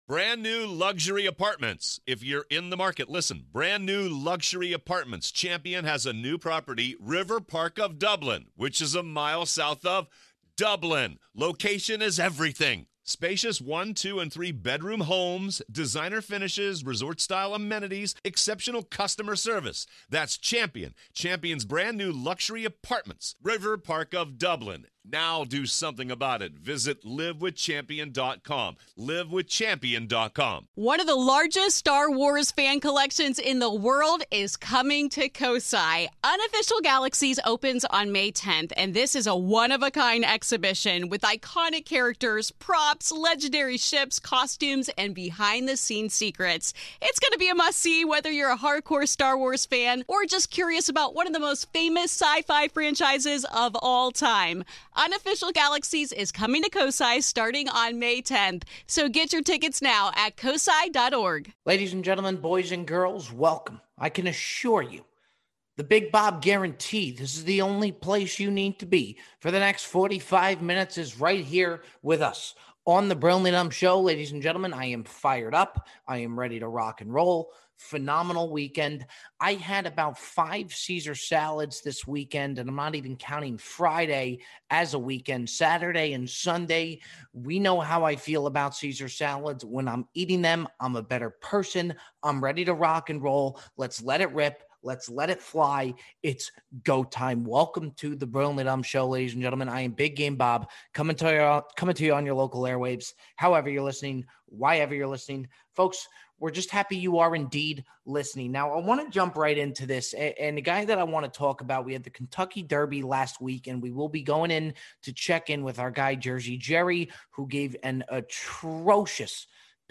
Voicemails close the show (28:09).